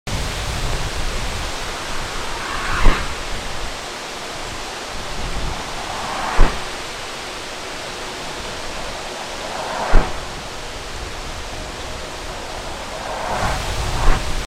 Photos on Google Pixel Come sound effects free download